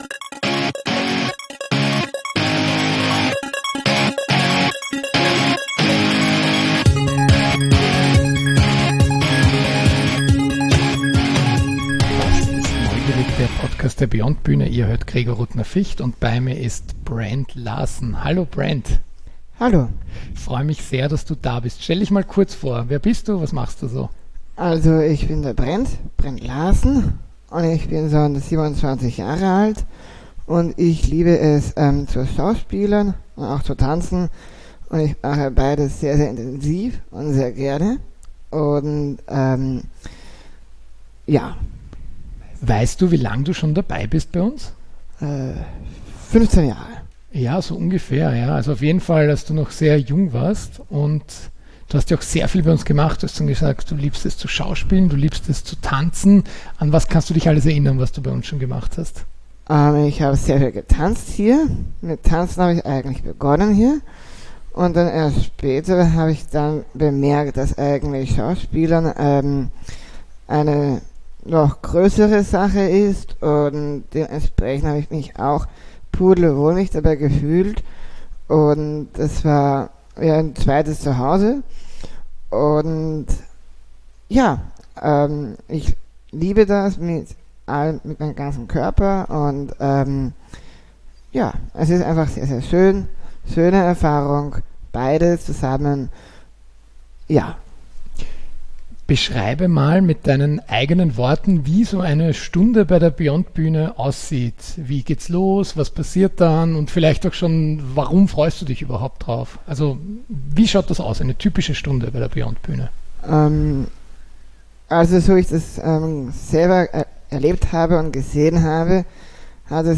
Interview mit unserem langjährigen Mitglied